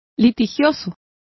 Complete with pronunciation of the translation of litigious.